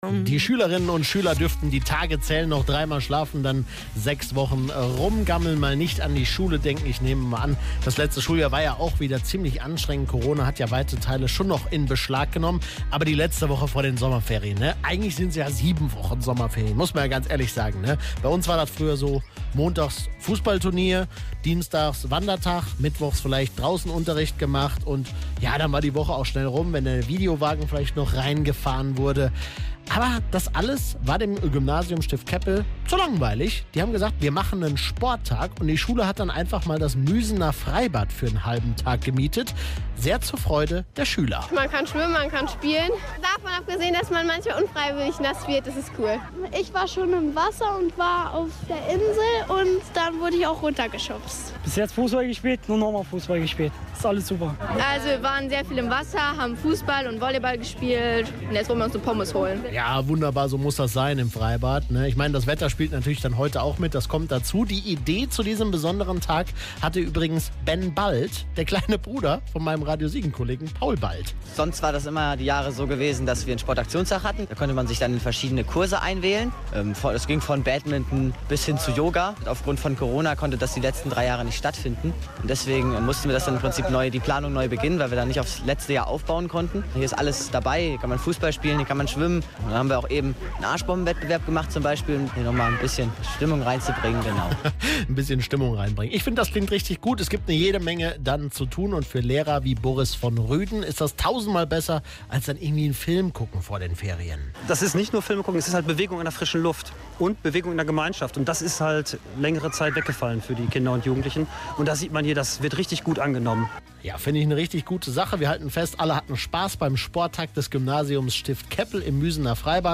Vielen Dank auch an Radio Siegen für die Reportage live vor Ort!
Radio Siegen Reportage